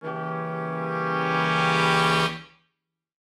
Index of /musicradar/gangster-sting-samples/Chord Hits/Horn Swells
GS_HornSwell-Gdim.wav